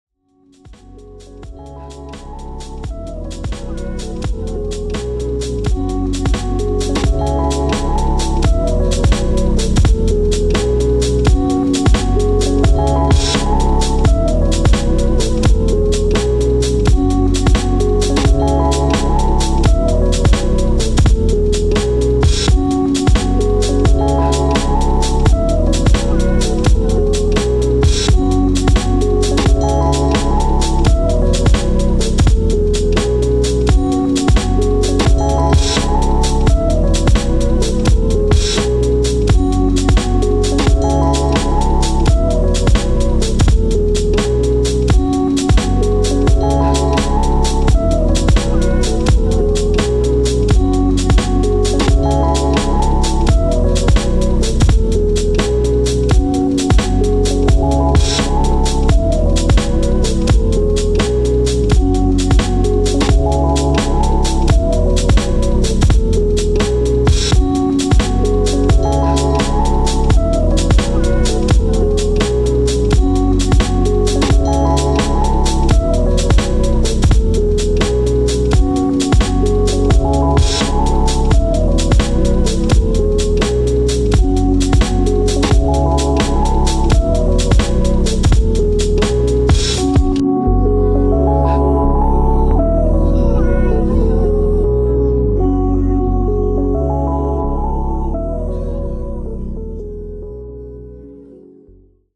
Work in progress loop…
with an awesome bass exit at the end